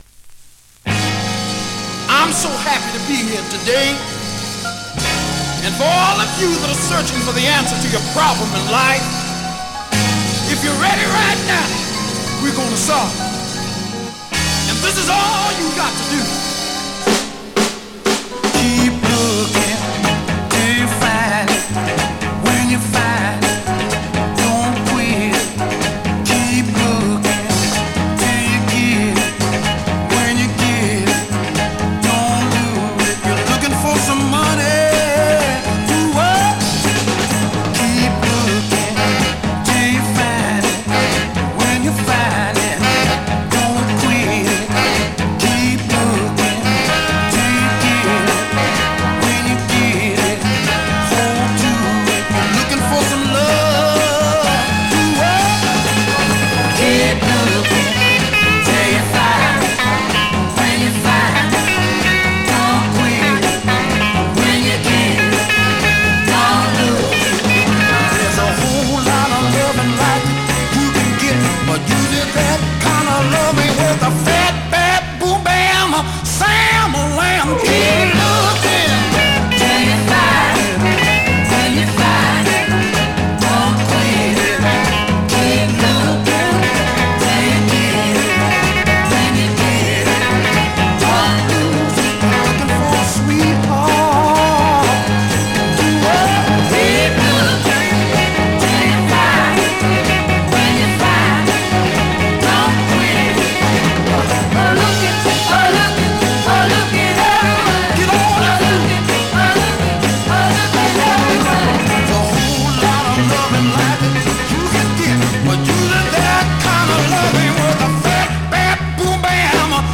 Vinyl has a few very light marks plays great .
Great classic mid-tempo Northern /Mod dancer
R&B, MOD, POPCORN , SOUL